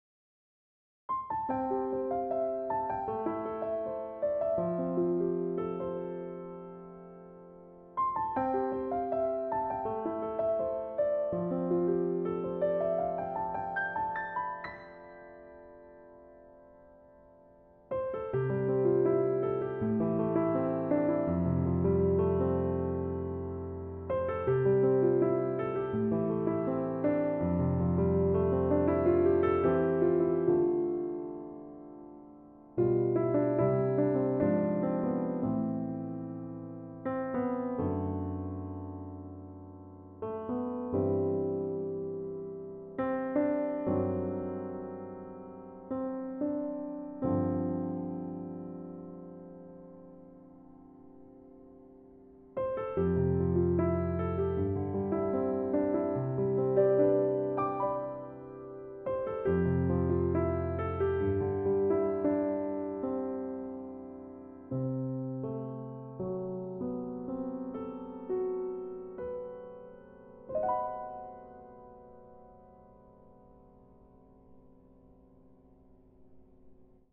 時にシンプルで 時に複雑なひびき 重なりあう音色の変化 ジャジーなテンション 人間らしいアナログな間